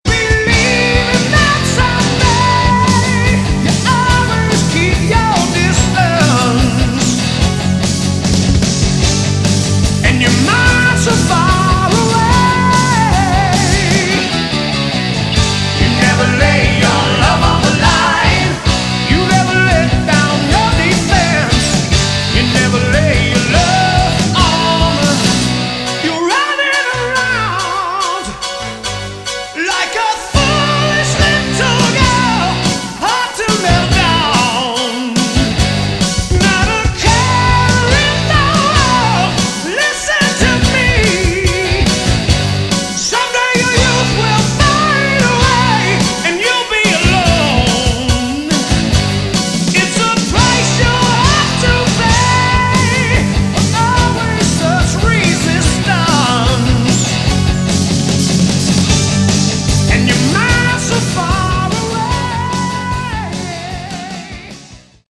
Category: Melodic Rock
vocals
keyboards, guitar
bass
drums